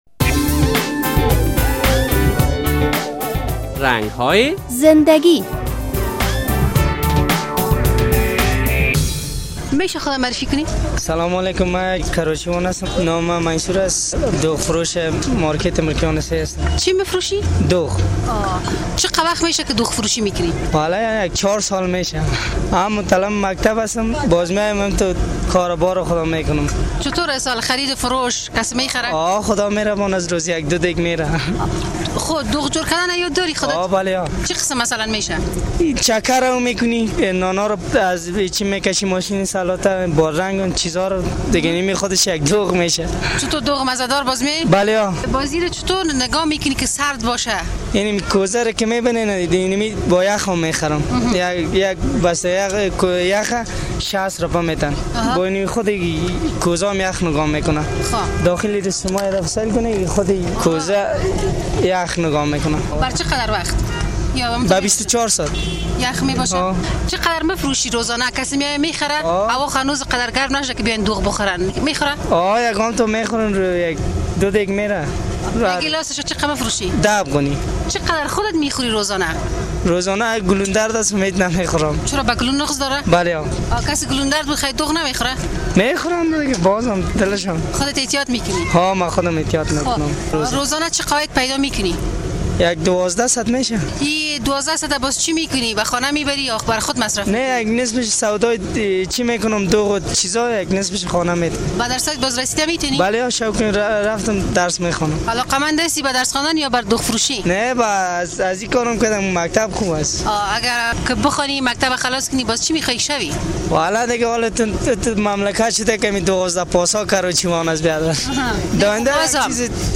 در این روز های گرم فصل سال، هنگام عبور از کنار سرک های شهر، نوشابه فروشان و دوغ فروشان توجه هر عابر را به خود جلب می کند. در این برنامه با یک تن از دوغ فروشان کنار سرک مصاحبه کرده ایم: